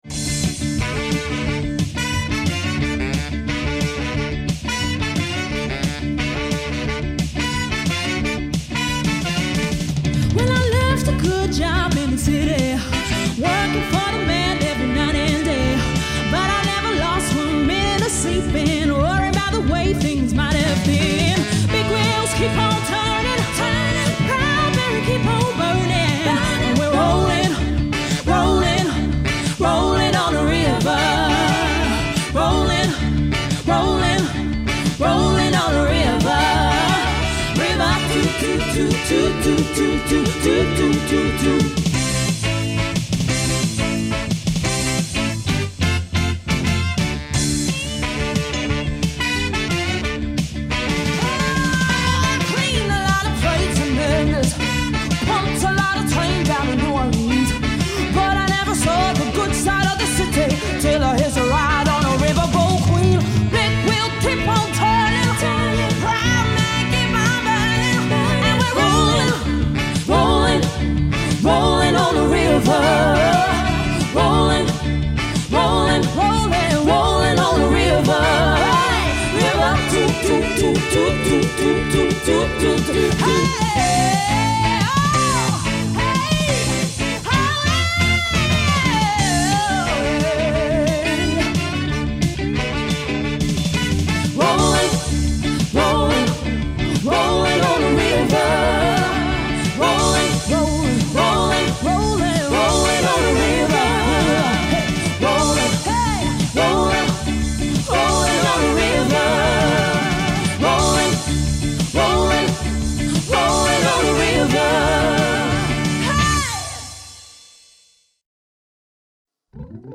female vocal trio